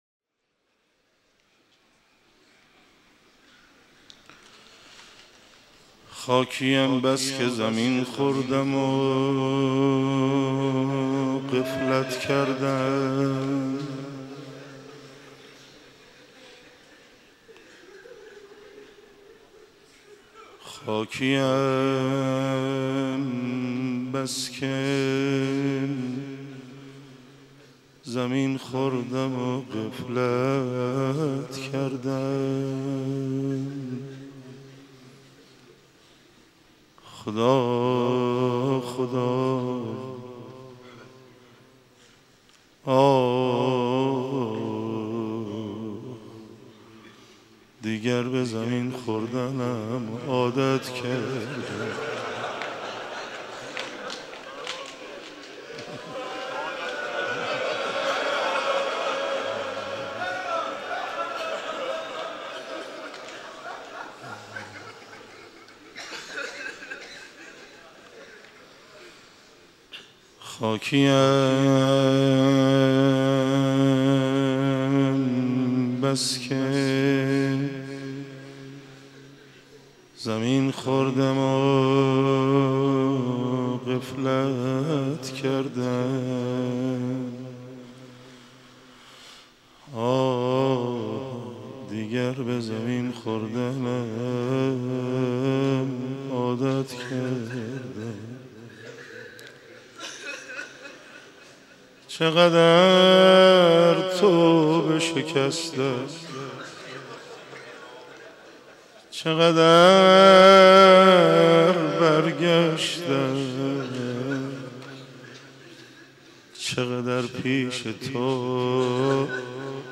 مداحی
در مسجد حضرت امیر(ع) برگزار گردید